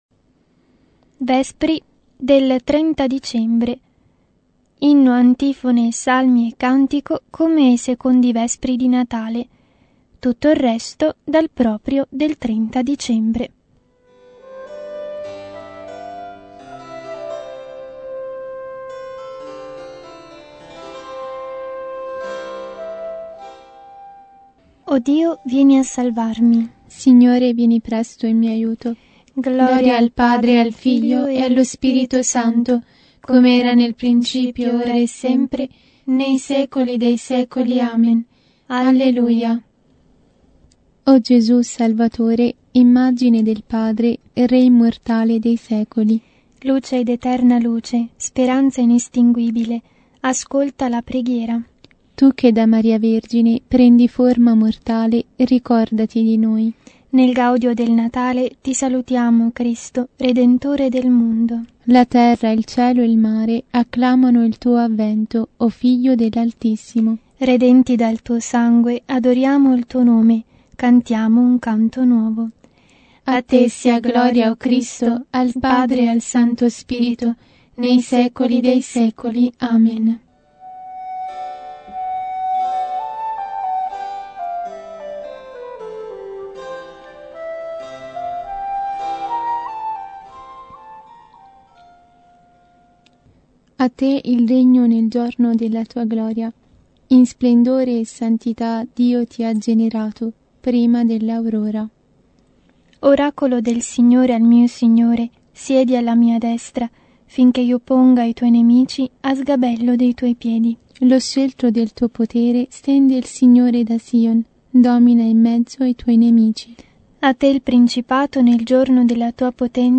Vespri – 30 dicembre
Liturgia delle Ore